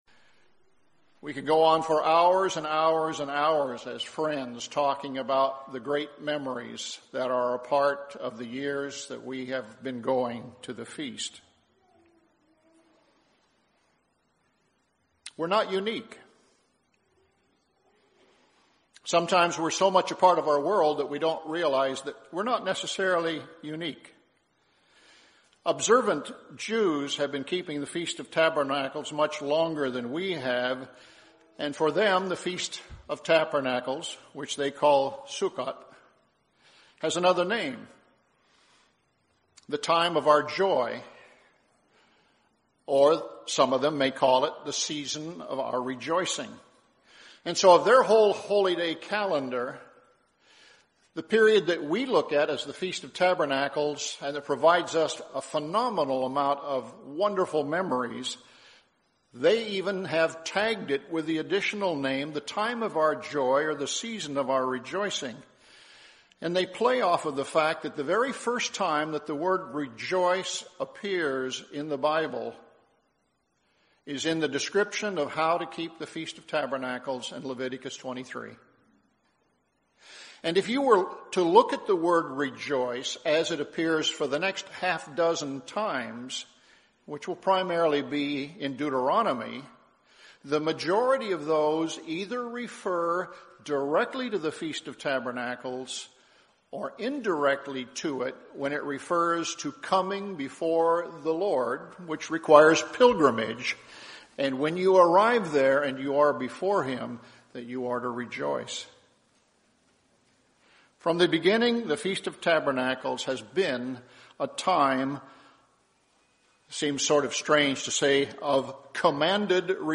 This sermon explores the Book of Ecclesiastes and how it can enhance even our observance of the Feast of Tabernacles.